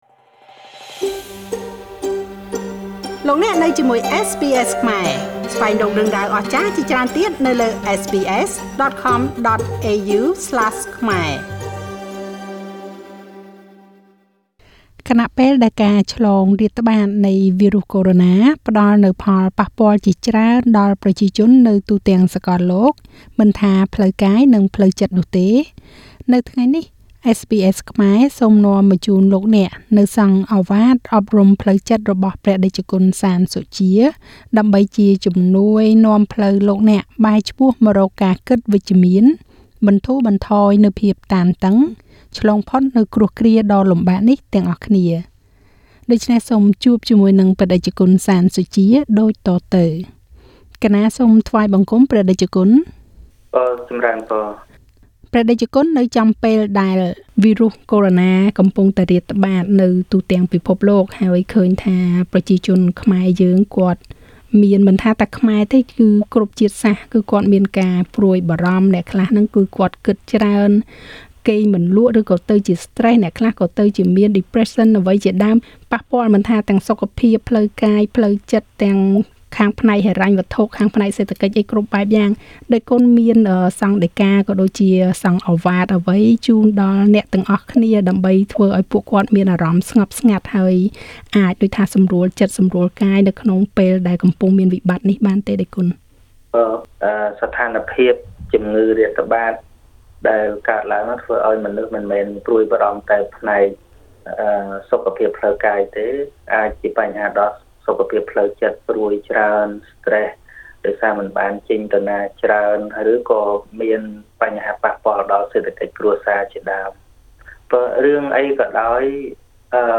ព្រះតេជគុណ សាន សុជា ផ្តល់ឱវាទអប់រំផ្លូវចិត្ត អំឡុងពេលដែលវីរុសកូរ៉ូណារាតត្បាត
ខណៈពេលដែលការឆ្លងរាតត្បាតនៃវីរុសកូរ៉ូណាផ្តល់នូវផលប៉ះពាល់ជាច្រើនដល់ប្រជាជននៅទូទាំងសកលលោក ទាំងផ្លូវចិត្តនិងផ្លូវកាយនោះ។ នៅថ្ងៃនេះ SBSខ្មែរសូមនាំមកជូនលោកអ្នក នូវសង្ឃឱវាទអប់រំផ្លូវចិត្តរបស់ព្រះតេជគុណ សាន សុជា ដើម្បីជាជំនួយនាំផ្លូវលោកអ្នក បែរឆ្ពោះមករកការគិតជាវិជ្ជមាន បន្ធូរបន្ថយភាពតានតឹង ឆ្លងផុតនូវគ្រោះគ្រាដ៏លំបាកនេះទាំងអស់គ្នា។